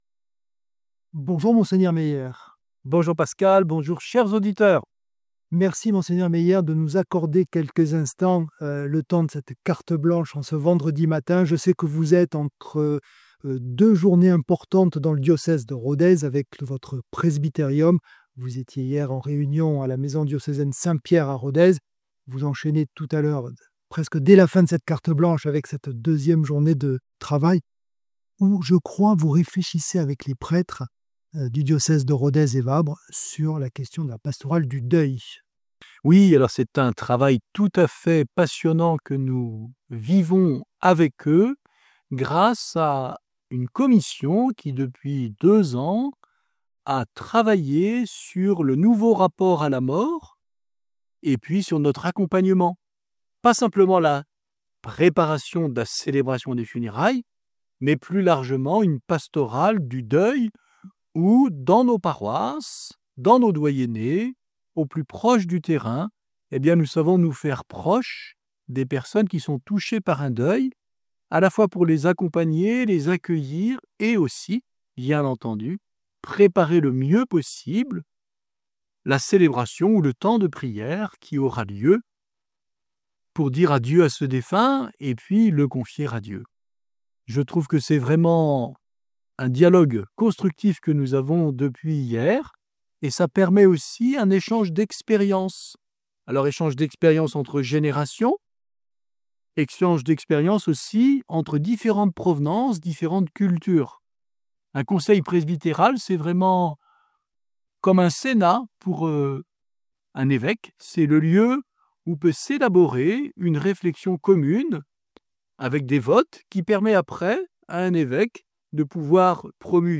Partager Copier ce code (Ctrl+C) pour l'intégrer dans votre page : Commander sur CD Une émission présentée par Mgr Luc Meyer Evêque du diocèse de Rodez et Vabres Voir la grille des programmes Nous contacter Réagir à cette émission Cliquez ici Qui êtes-vous ?